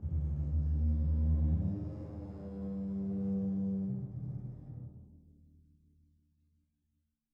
bassdrum_rub4_v1.wav